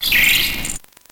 Cri de Tic dans Pokémon Noir et Blanc.